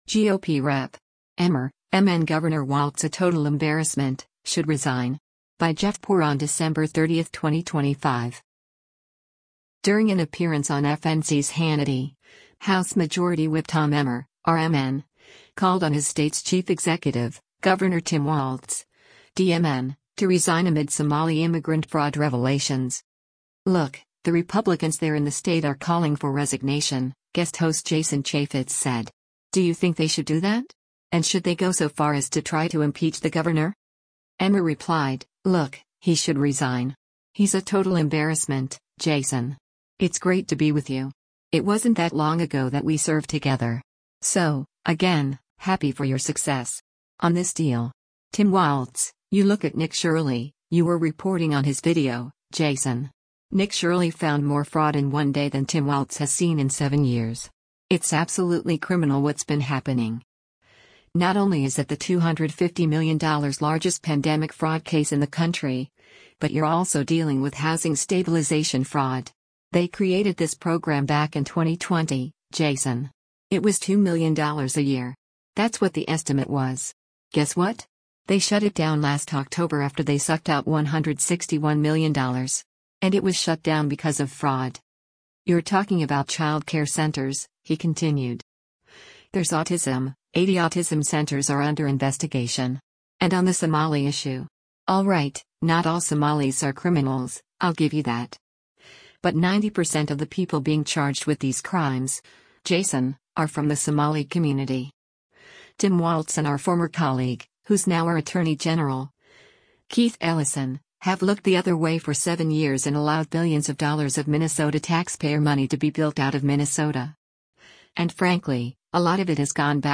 During an appearance on FNC’s “Hannity,” House Majority Whip Tom Emmer (R-MN) called on his state’s chief executive, Gov. Tim Walz (D-MN), to resign amid Somali immigrant fraud revelations.